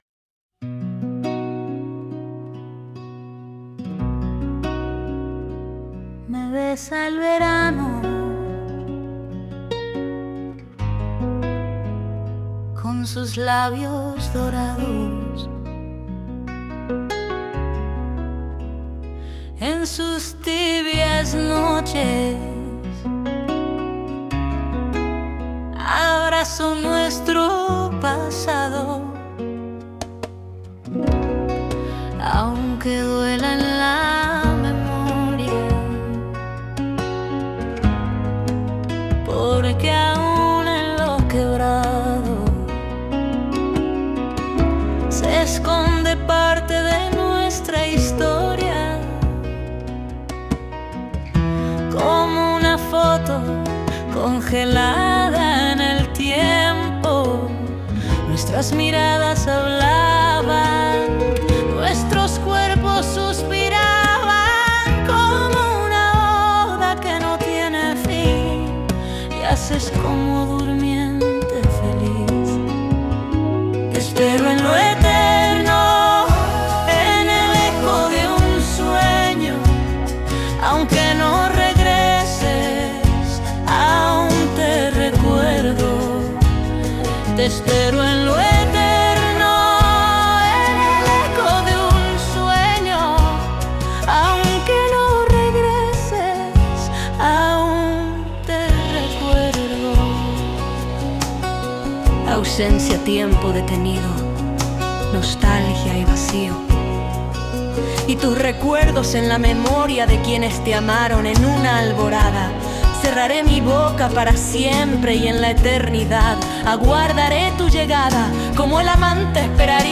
Ecos de un sueño pasó de ser un Cadáver exquisito a una canción: